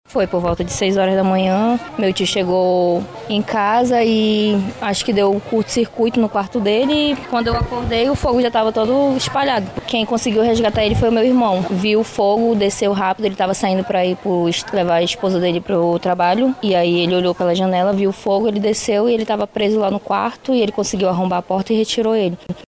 SONORA-SOBRINHA.mp3